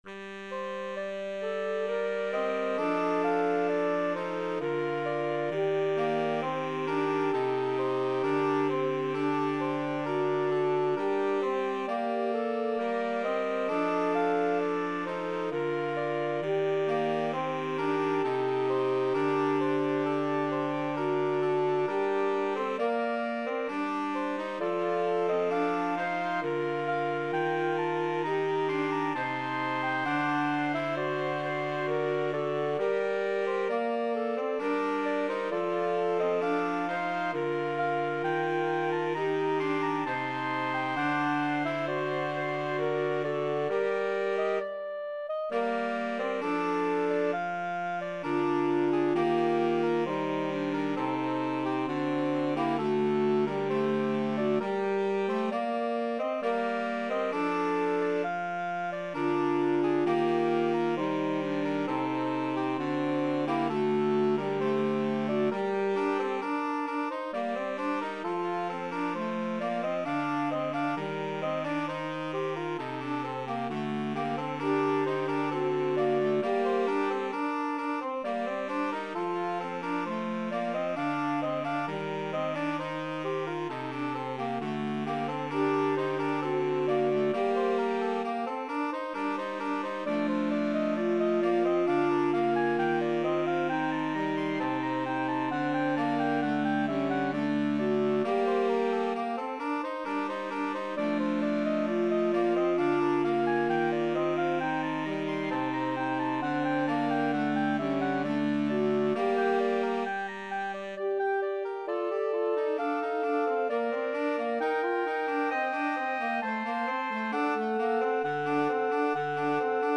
Bb major (Sounding Pitch) (View more Bb major Music for Saxophone Quartet )
3/4 (View more 3/4 Music)
Saxophone Quartet  (View more Advanced Saxophone Quartet Music)
Classical (View more Classical Saxophone Quartet Music)
buxtehude_chaconne_em_buxwv160_SAXQ.mp3